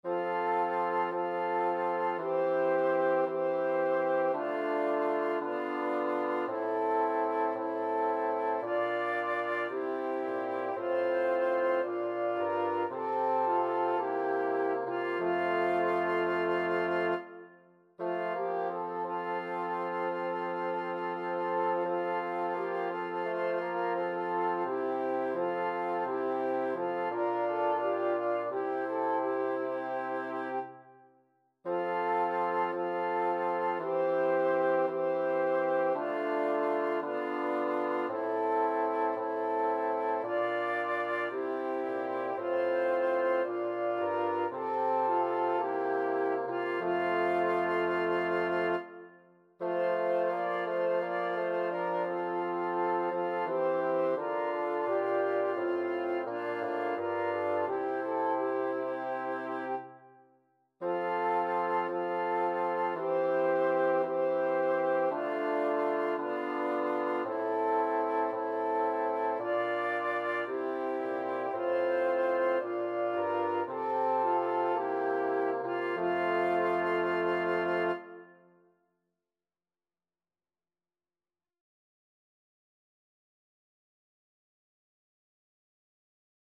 Title: Christus vincit Composer: Aymé Kunc Number of voices: 4vv Voicing: SATB Genre: Sacred, Hymn Meter: 445 or 87
Language: Latin Instruments: Organ